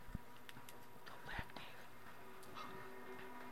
A spirit says "Go back, David" to Reedsburg Mayor David Estes